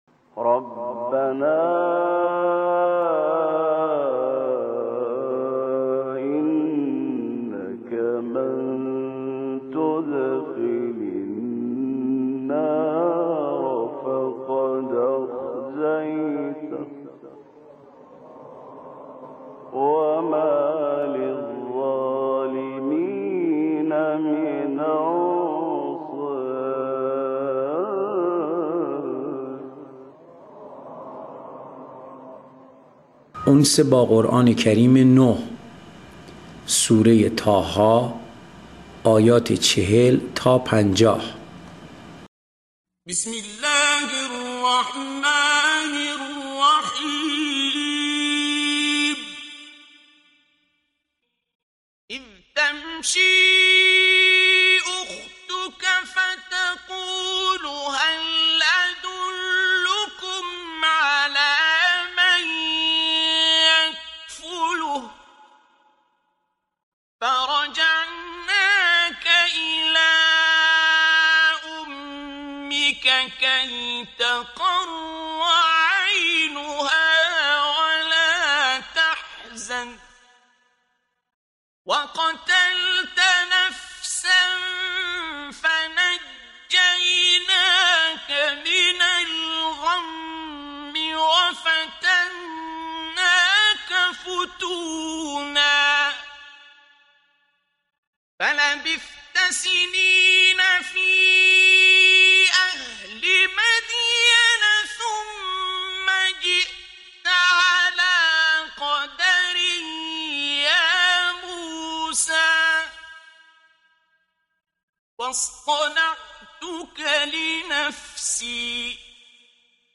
قرائت آیات آیات 40 الی 47 سوره طه